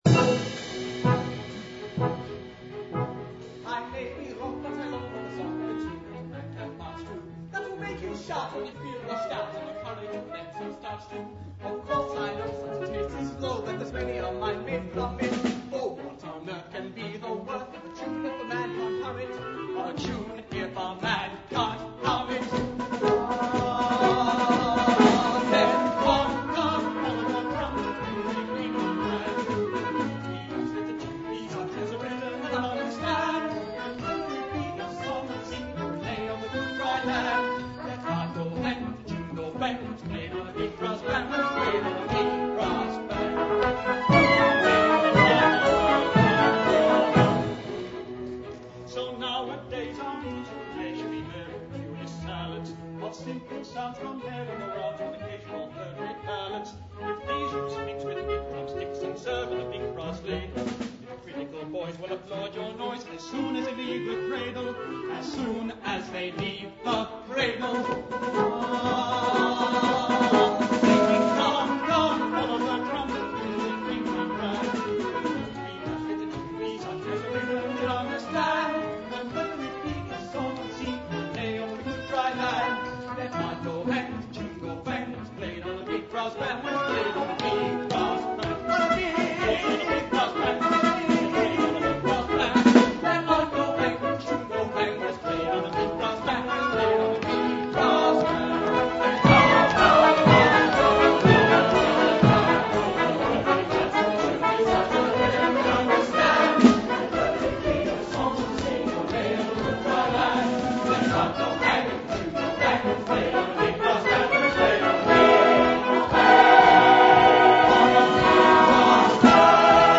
Jeeves Audio Services is pleased to be associated with the Gilbert and Sullivan Society of Victoria, making live recordings of the society's productions.